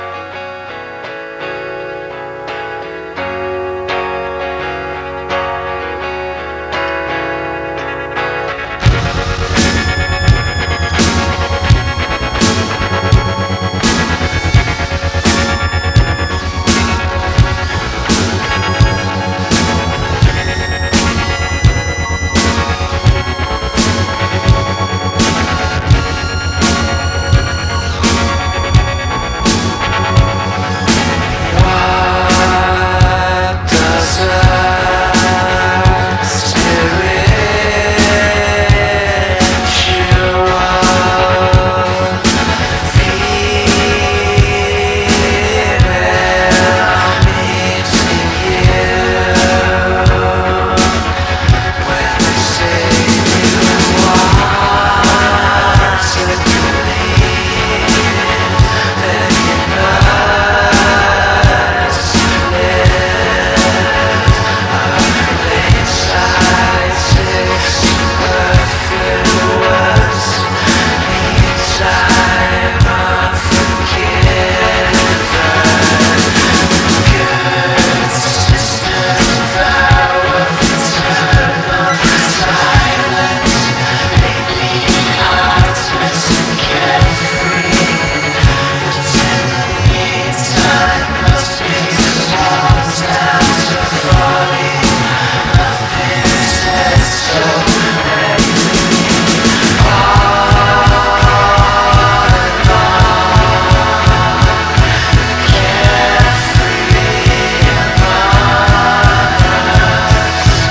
Ethereal Alternative Rock / Post Punk